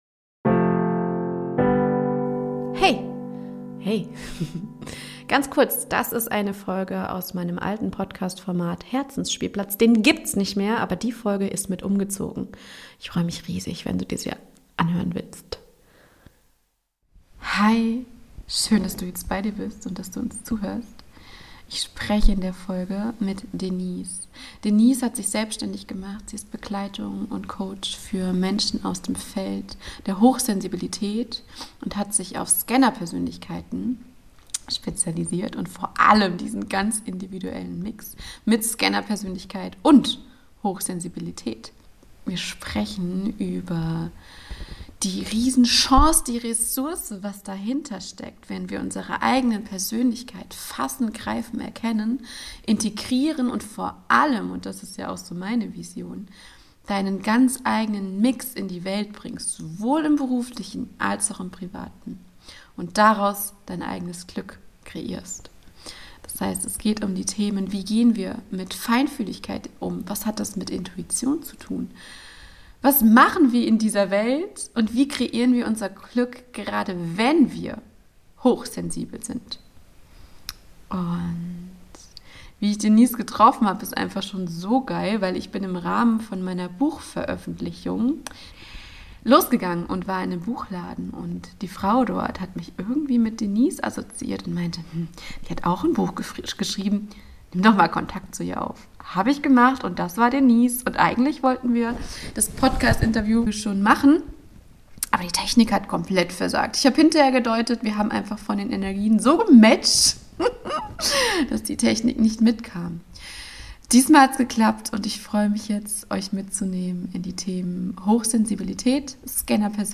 Interview: Hochsensibilität als Superkraft - baue Dein eigenes Glück